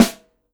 CYCdh_K4-Snr05.wav